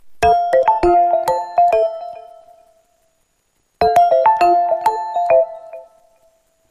• Качество: 112, Stereo
Стандартный рингтон